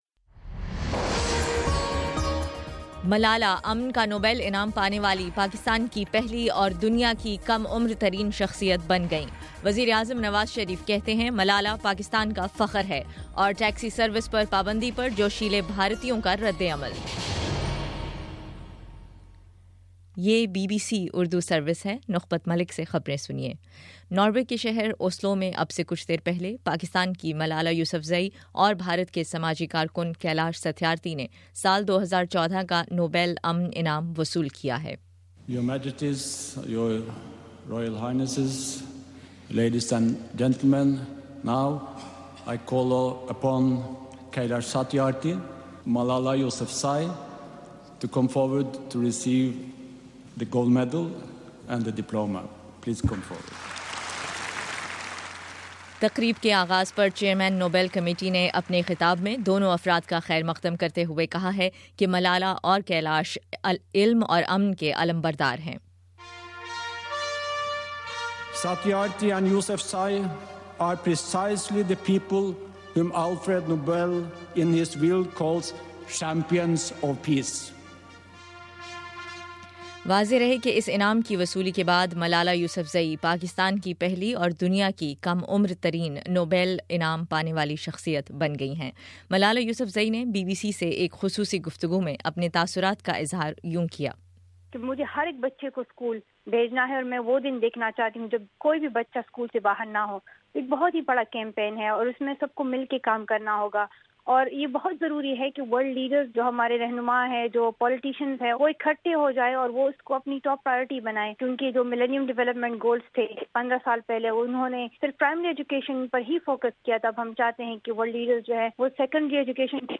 دسمبر10: شام چھ بجے کا نیوز بُلیٹن